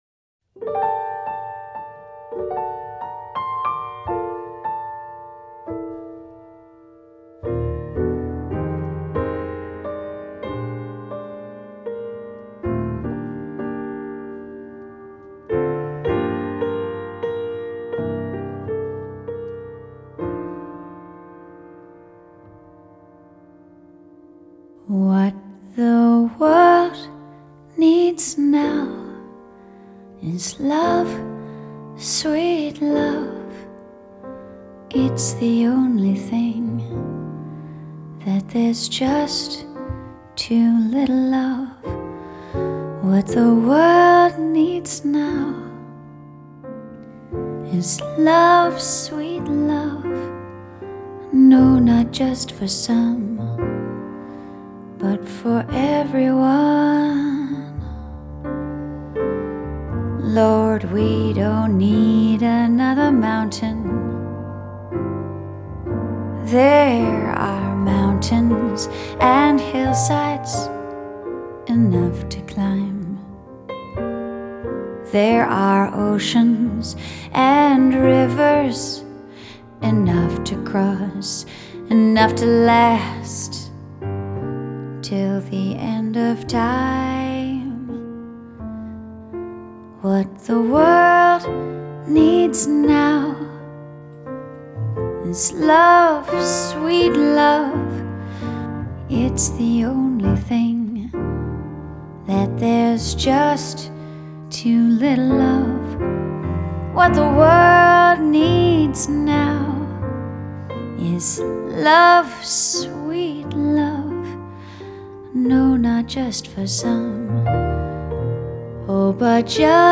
擁有醇熟歌聲的小情人